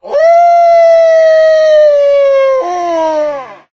wolf
howl2.ogg